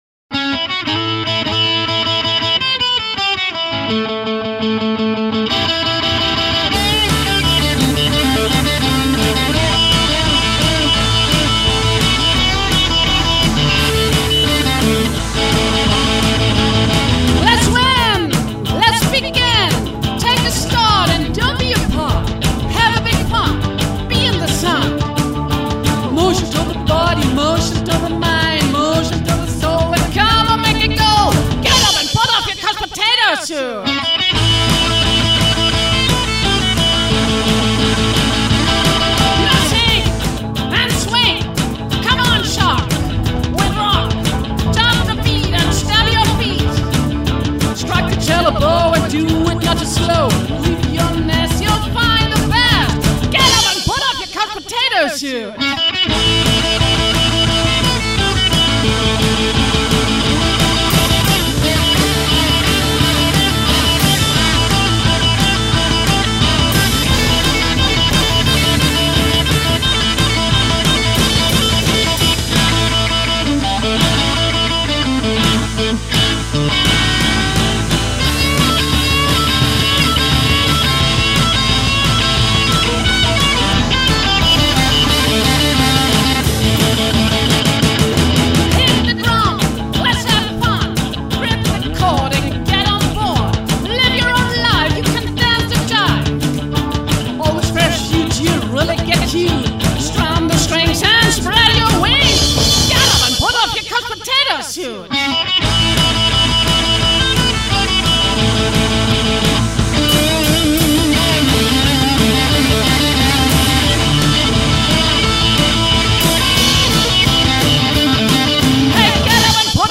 Drums & E-Gitarre